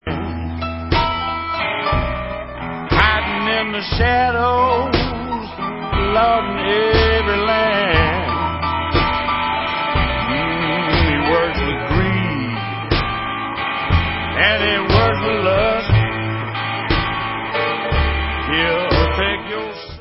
New studio album